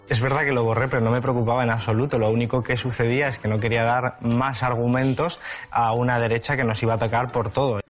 Declaraciones de Garzón sobre el tuit que borró